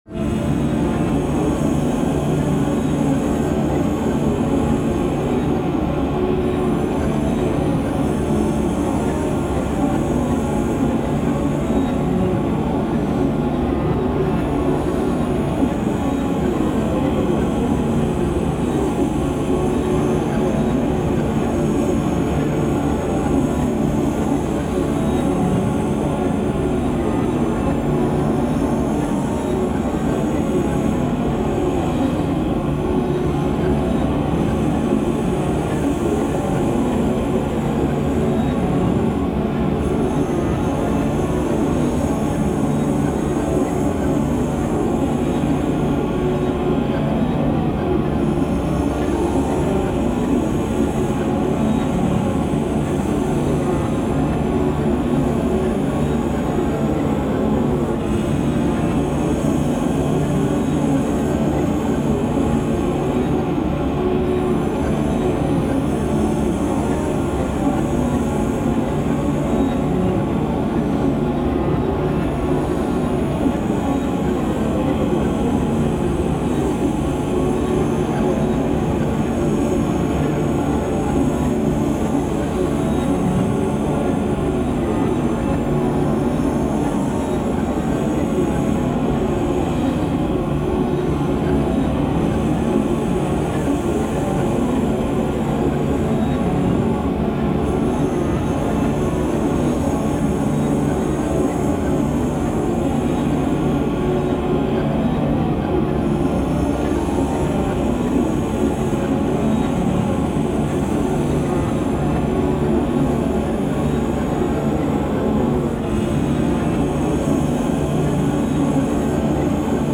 5段階のホラーテクスチャ音源の第二段階。 少し様子が変わって異形の声のようなものがよりはっきりと聞こえるようになる。
タグ: ホラー/怖い 変わり種 コメント: 5段階のホラーテクスチャ音源の第二段階。